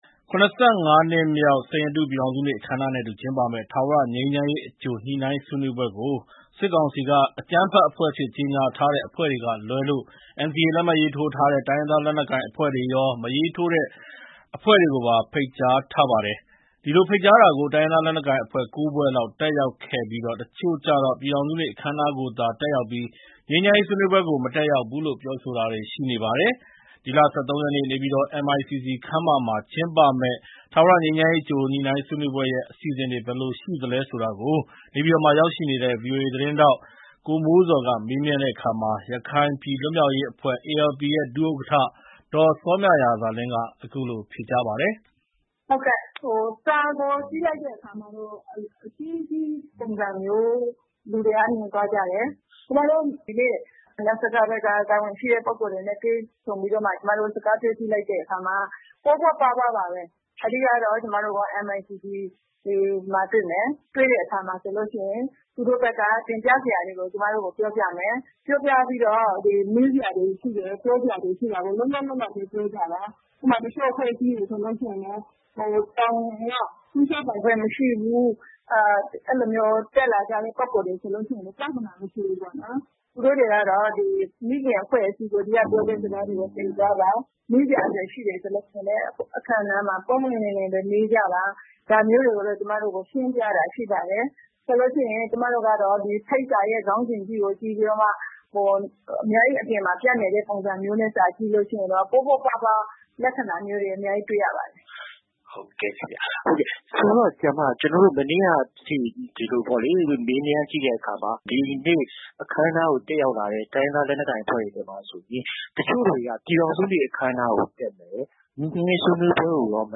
စစ်ကောင်စီရဲ့ ငြိမ်းချမ်းရေး အကြိုဆွေးနွေးပွဲ အလားအလာ မေးမြန်းချက်